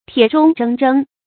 鐵中錚錚 注音： ㄊㄧㄝ ˇ ㄓㄨㄙ ㄓㄥ ㄓㄥ 讀音讀法： 意思解釋： 錚錚：金屬器皿相碰的聲音。比喻才能出眾的人。